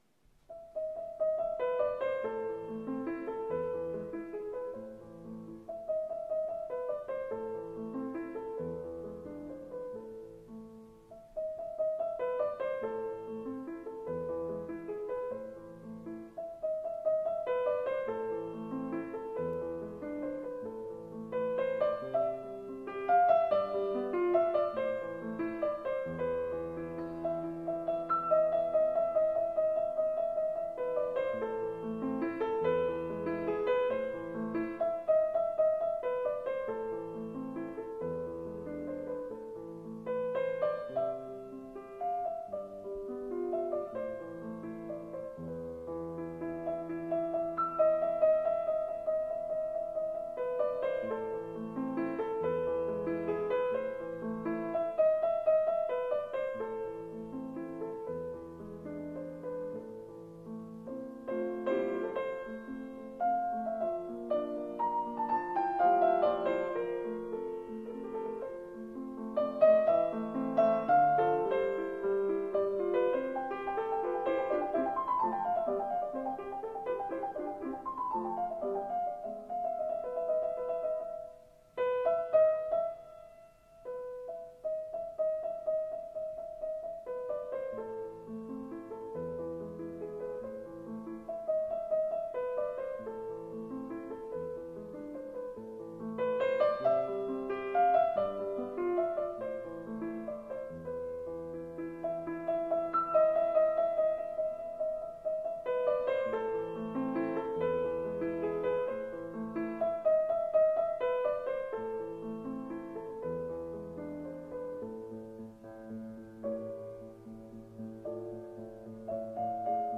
音色清澈透明，旋律柔和动人。